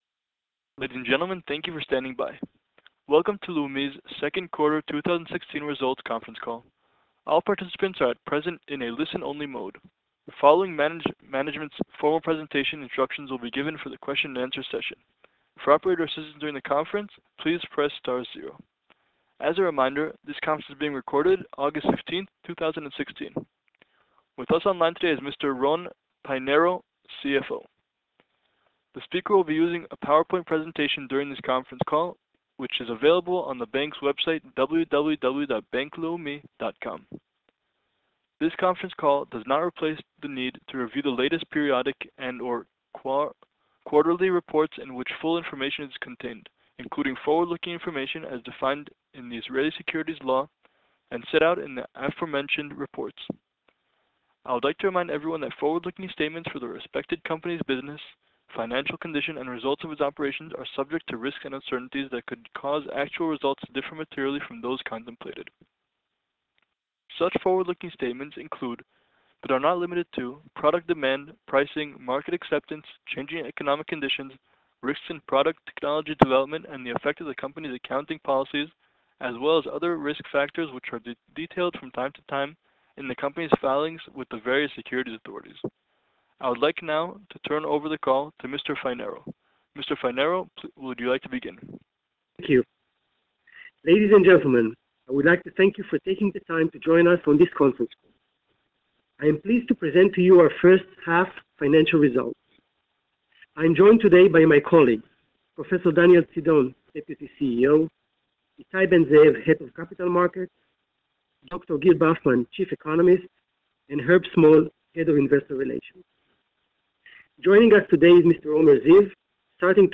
Investor Presentation and Conference Call
Bank_Leumi_Q2_2016_Results_Conference_Call.wma